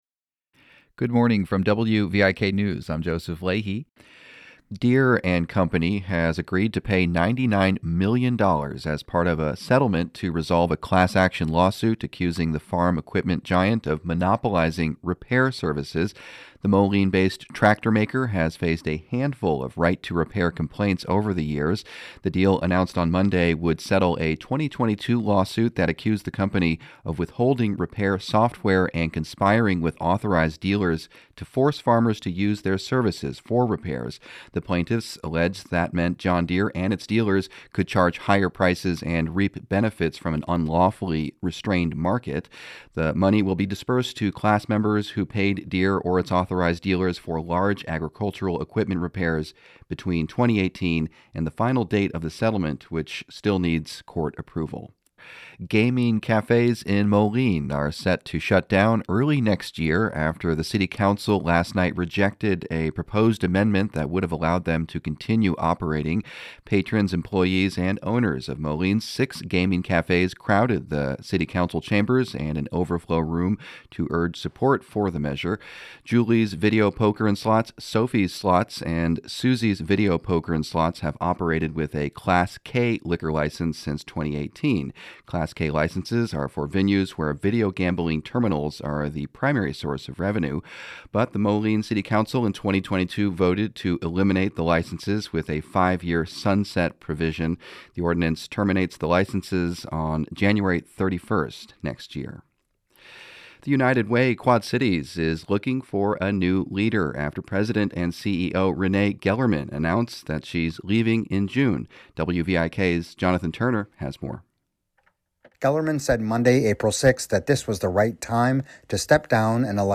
Morning headlines from WVIK News.
Genres: Daily News, News